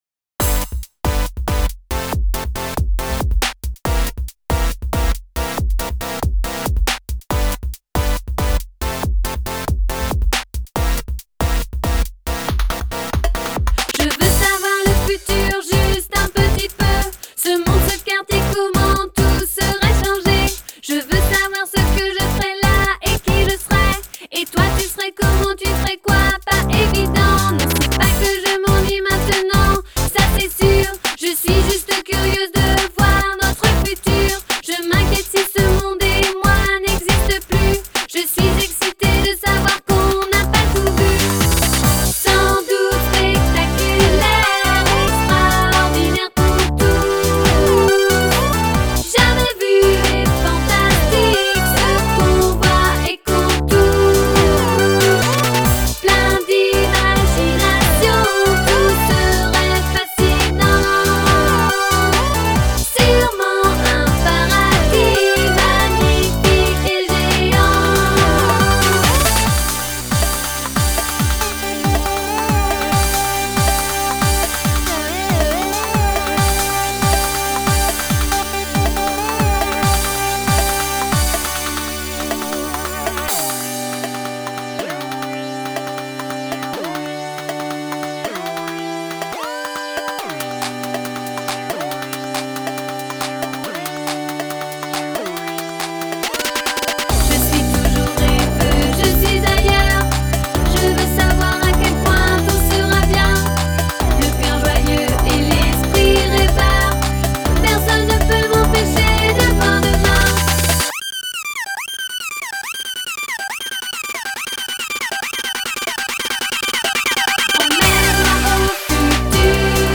BPM139
Audio QualityPerfect (High Quality)
Nothing like a bit of French to make me think of some EDM